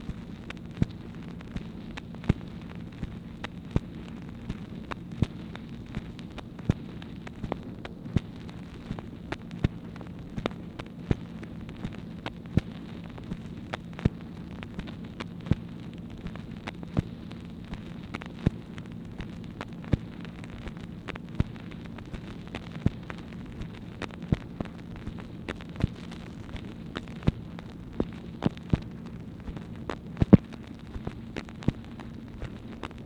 MACHINE NOISE, April 30, 1964
Secret White House Tapes | Lyndon B. Johnson Presidency